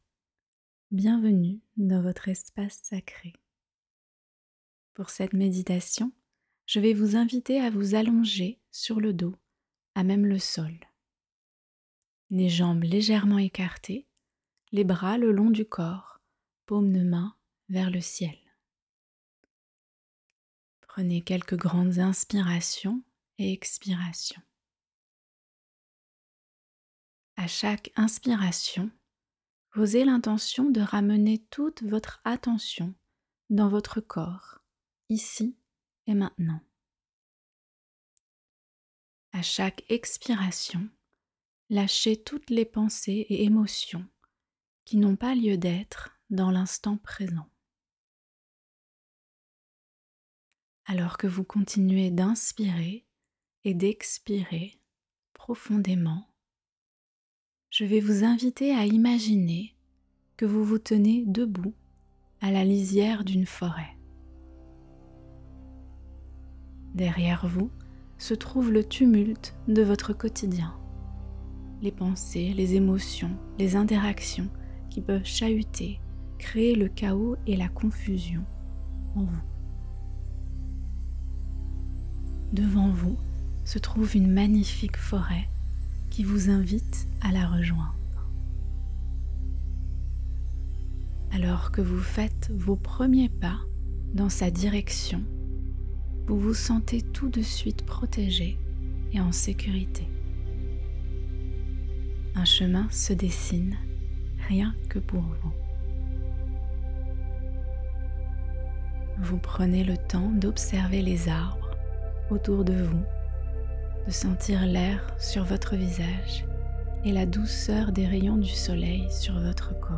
MÉDITATION GUIDÉE | Retrouver le calme intérieur
Une méditation guidée pour calmer le tumulte du mental et se recentrer. Un voyage au coeur de la forêt pour se déposer et décharger toutes les pensées, émotions et énergies qui ne vous servent pas.
meditation-guidee-retrouver-le-calme-interieur.mp3